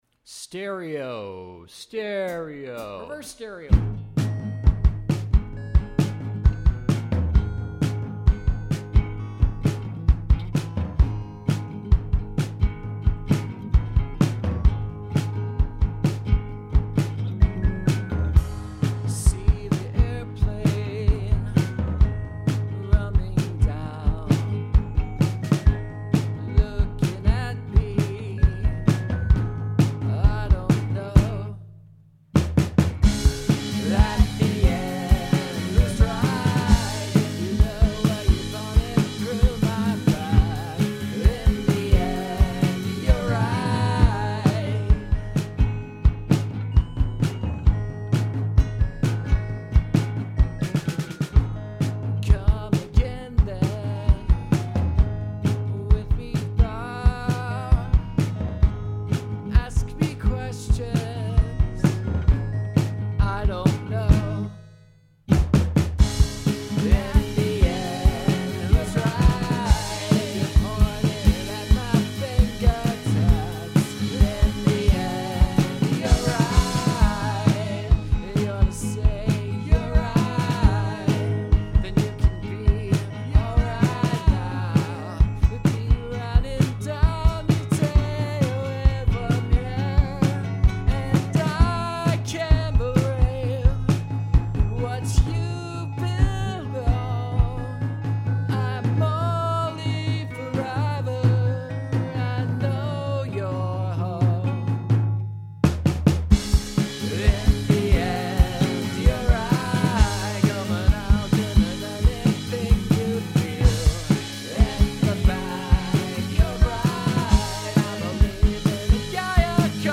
Then it was 4pm and I had work at 6pm, so I recorded the drums in a quick take, chopped them up to make the structure I wanted, quickly figured out the chords (capo on the 4th fret), kept the chords simple since I didn't have time to worry about whether they were generic or not (they are).
I was so pleased with the results that I recorded the vocals this way, then one of my Casios.
Then I turned the mic over and recorded an ultra dirty backing vocal.
Also, this is a lot more jaunty than I usually am, I blame starting with this beat (which has stolen ideas from two songs I've played multiple times in Rock Band - "Maps" and "You Got It").
Part of what makes me like the way it comes in is that little quiet piano-bell keys sound in there. I also like all the cymbal work.